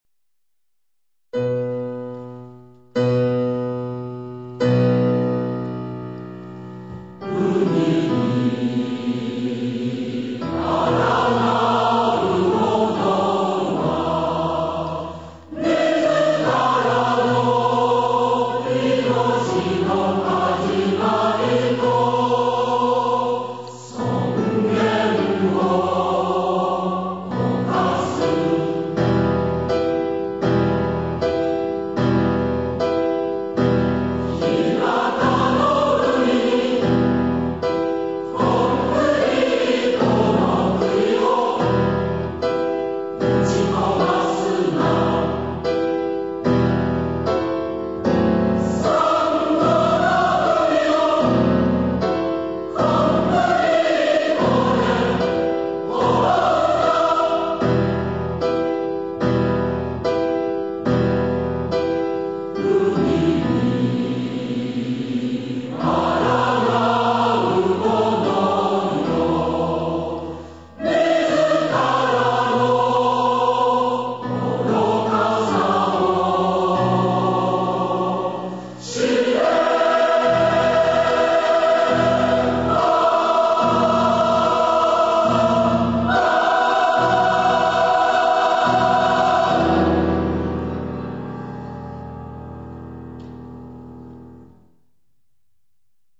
演奏：九州のうたごえ合同合唱団（２００３年九州のうたごえ大分祭典での録音）